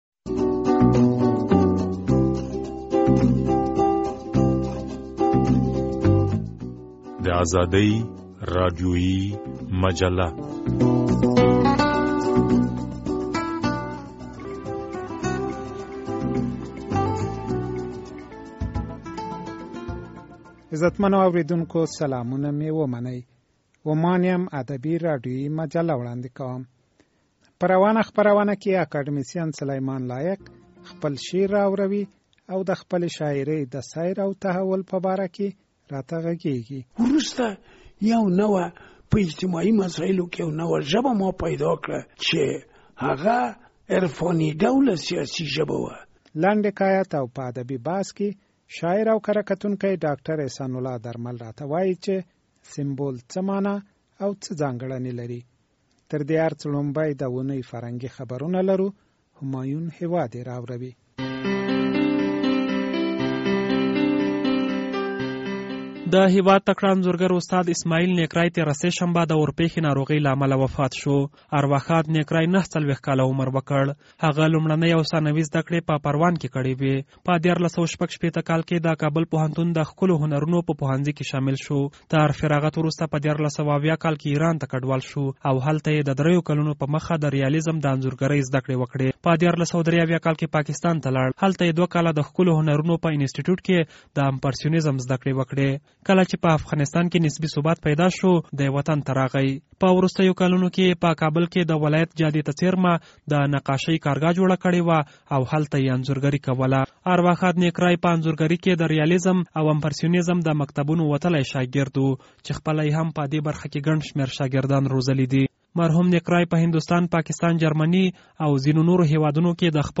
په نننۍ خپرونه کې اکاديمسین سليمان لايق خپل شعر رااوروي او د خپلې شاعرۍ د سير او تحول په باره کې غږېږي...